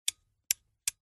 Звук включения нескольких лампочек